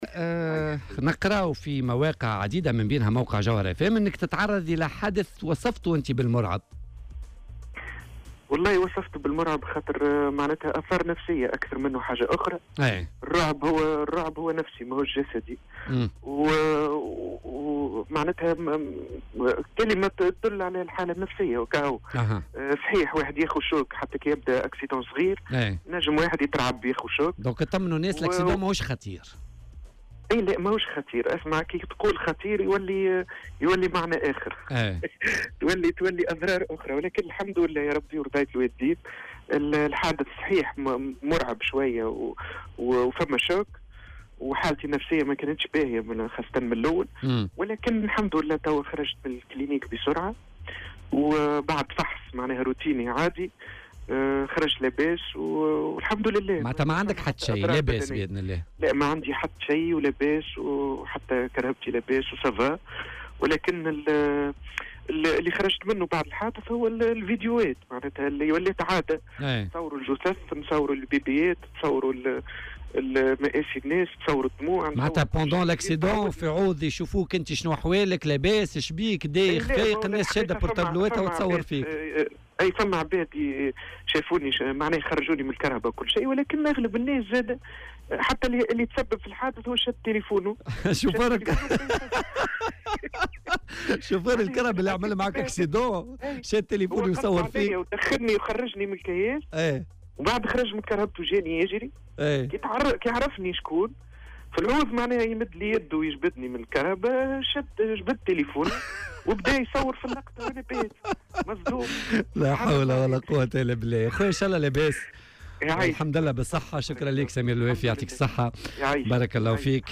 وأضاف في مداخلة له في برنامج "بوليتيكا" على "الجوهرة أف أم" أنه كان في حالة نفسية سيئة للغاية.